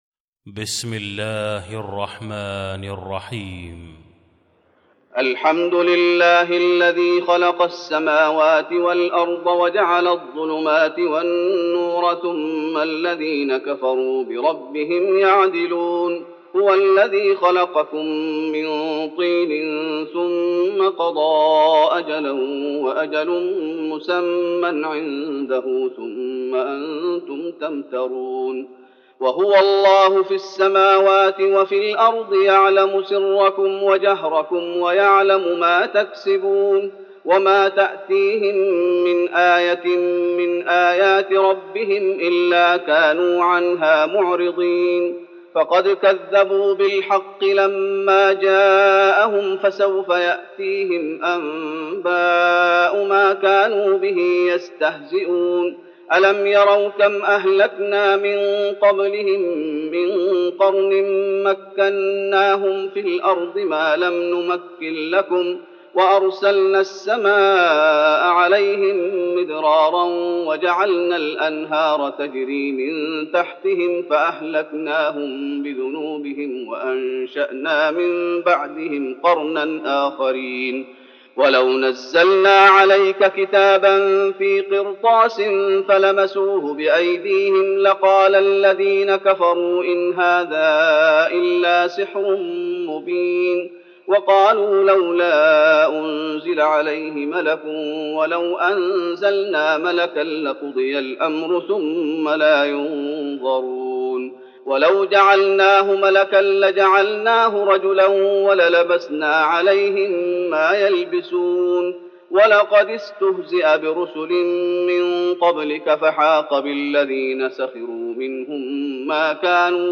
المكان: المسجد النبوي الأنعام